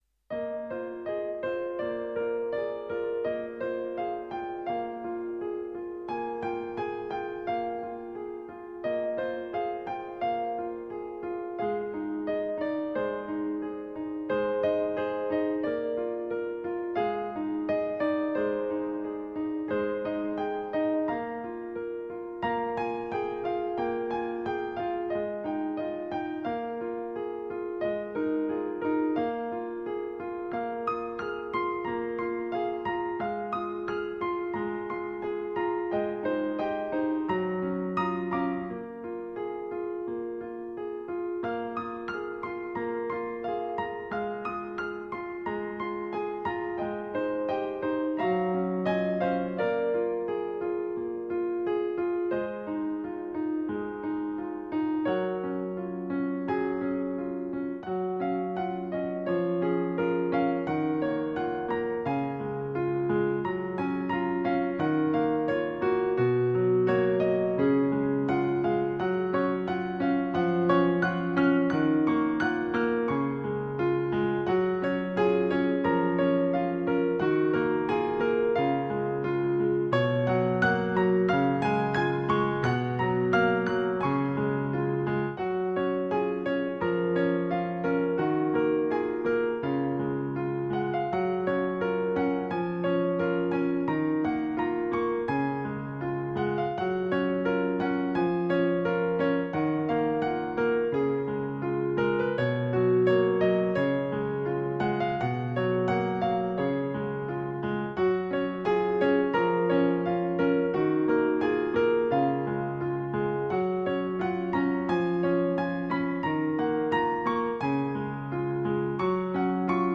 Solo Instrument